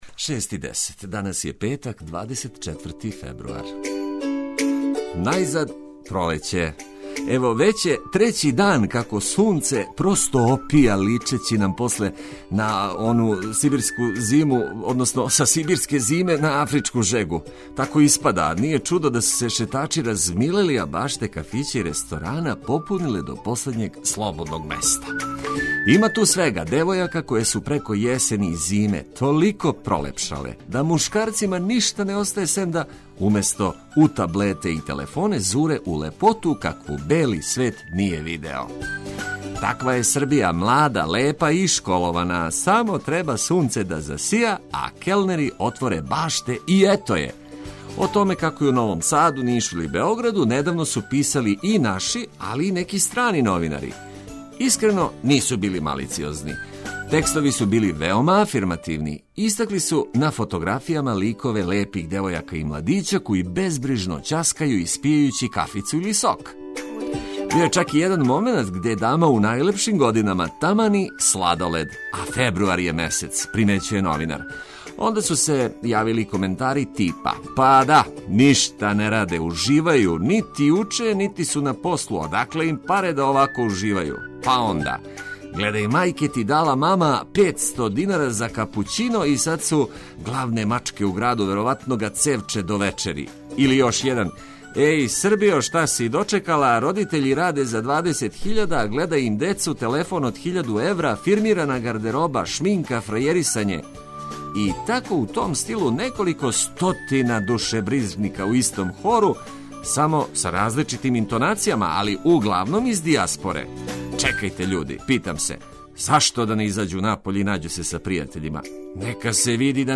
Срдачно добро јутро упутићемо свима онима који одлуче да уз ведру музику и корисне информације почну дан уз Београд 202.